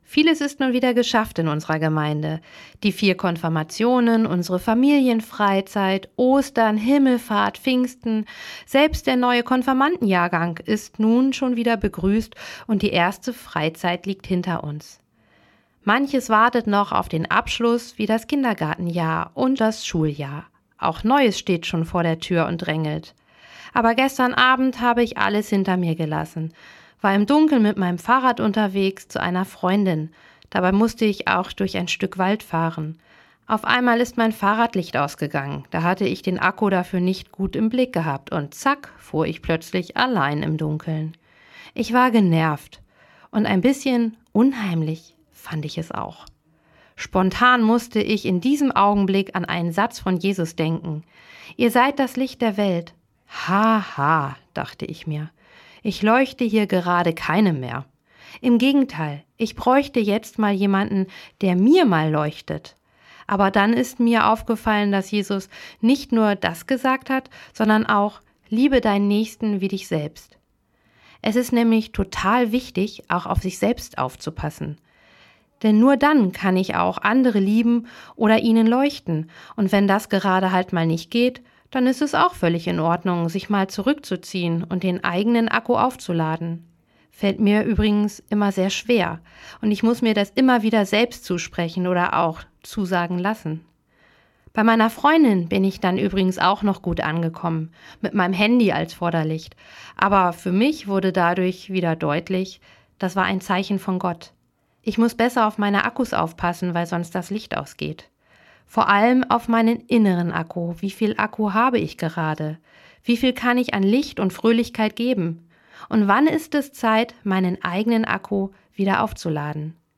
Radioandacht vom 16. Juni